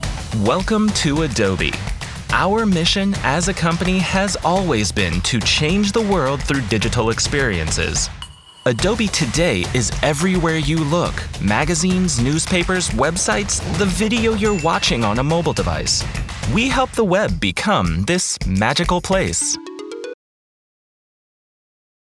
Inglés (Estados Unidos)
Vídeos corporativos
Adulto joven
Mediana edad